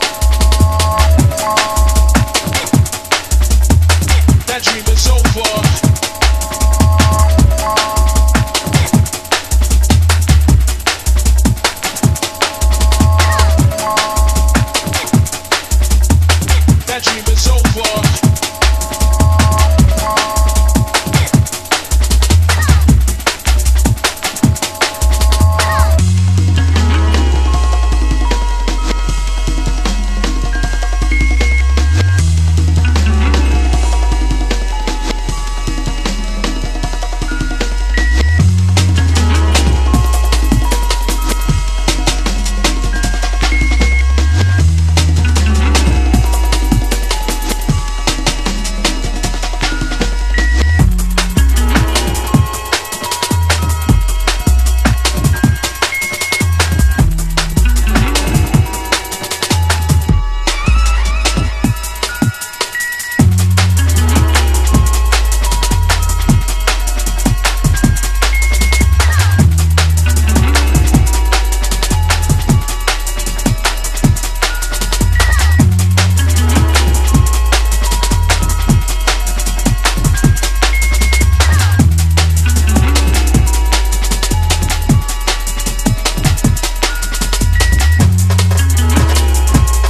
WORLD / LATIN / LATIN FUNK / LATIN JAZZ / LATIN BREAK
DJユースな強力ラテン・ファンクをたっぷり収録！